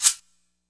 Metro Vibe_Shaker.wav